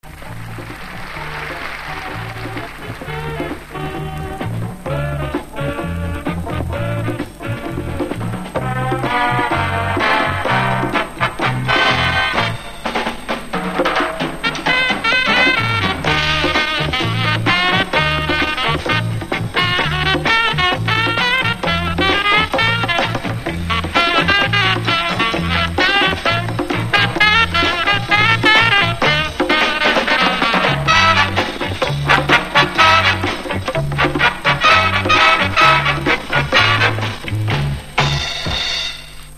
Фрагменты двух латиноамериканских танцевальных мелодий (запись 77-го года)
Предлагаю послушать короткие фрагменты танцевальных мелодий которые я записал с телевизора в 1977-м году (что это за танцы точно не знаю, но по видимому латиноамериканские).
latinoamerikanskaya-tantsevalnaya-melodiya-70-h-godov-2-(fragment).mp3